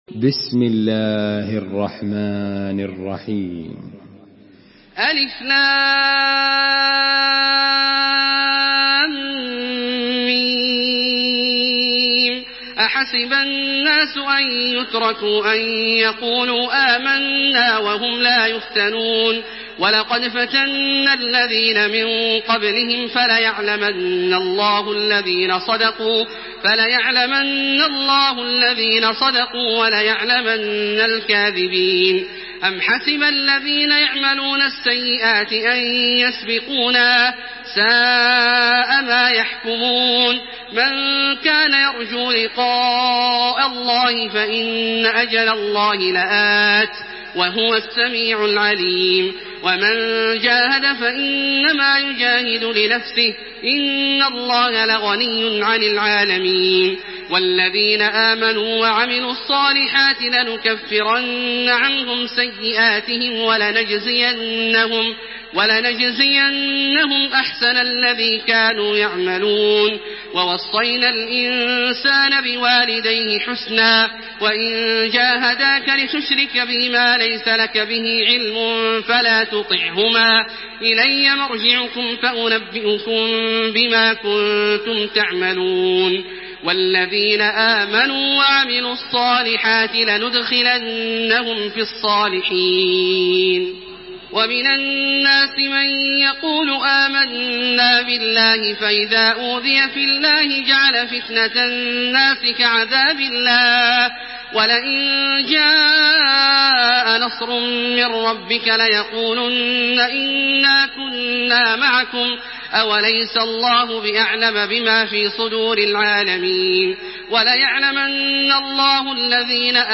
Surah Al-Ankabut MP3 by Makkah Taraweeh 1428 in Hafs An Asim narration.